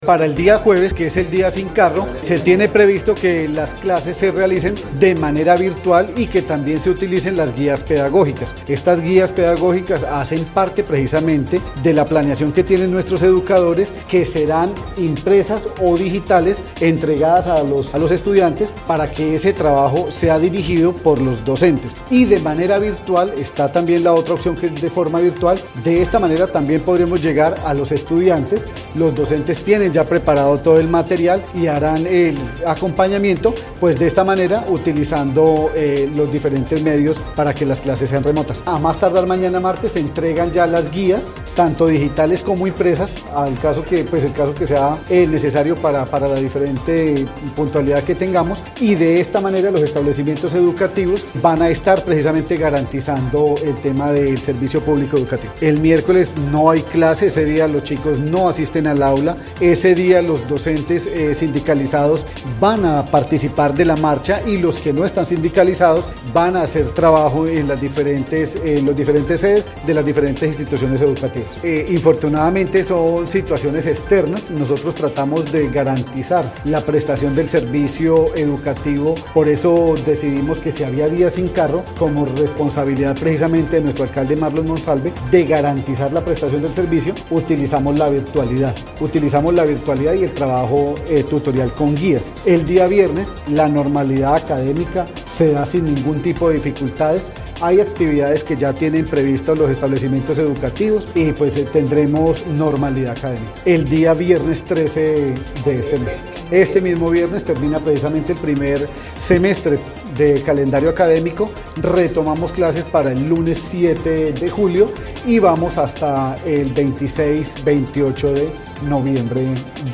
El secretario de Educación, Fener de los Ríos, precisó que, el miércoles 11 de junio, no habrá estudiantes en las aulas debido a la marcha convocada por la Asociación de Trabajadores de la Educación del Caquetá AICA, en apoyo a la Gran Movilización Nacional, con toma a Bogotá.